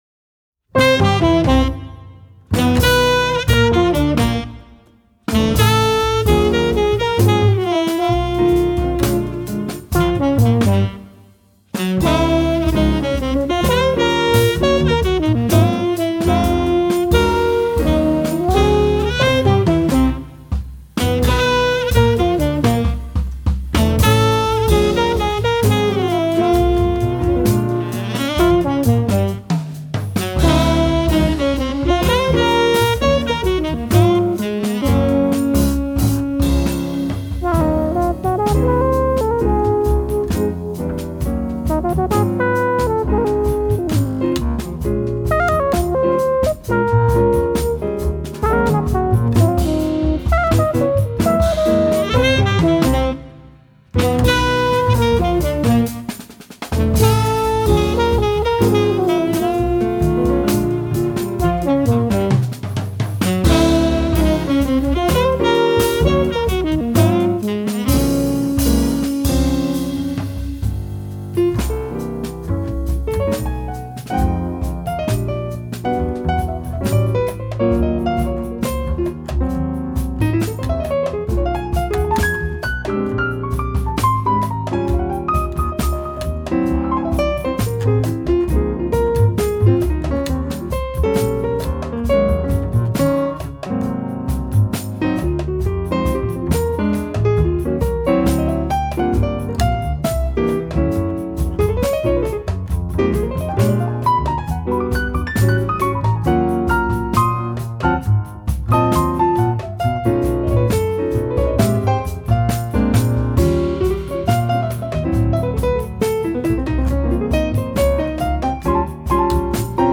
FILE: Jazz